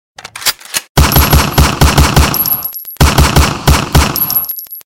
gun-shots_24808.mp3